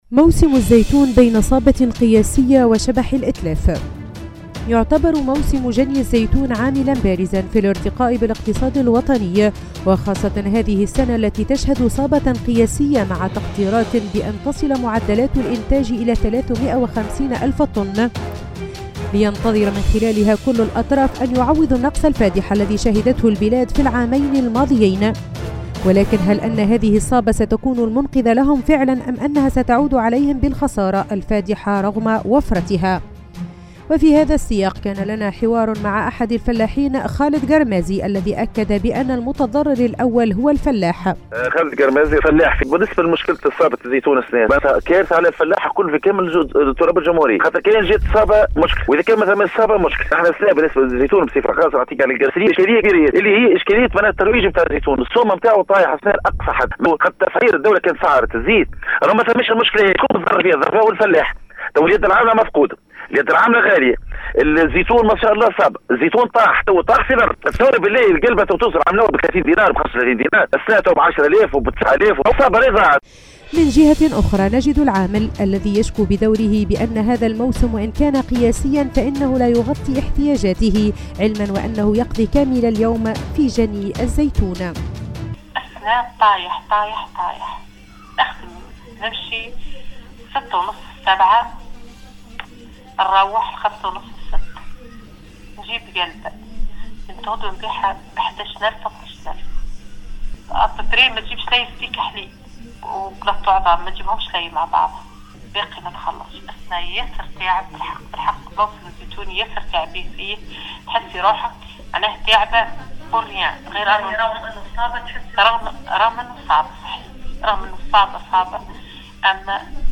تشهد صابة الزيتون لهذا العام عديد الاشكاليات رغم كونها صابة قياسية و هو ما اثار استياء اطراف عديدة لعل ابرزهم الفلاحين الذين قاموا بعديد الوقفات الاحتجاجية للمطالبة بانقاذ الصابة من الاتلاف. في هذا السياق نمرر  هذا التقرير